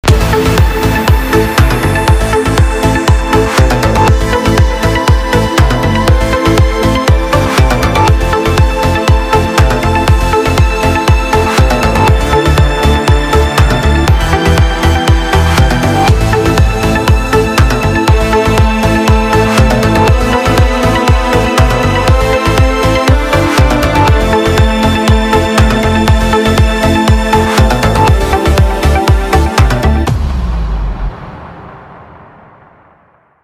• Качество: 320, Stereo
без слов
ритм
просто музыка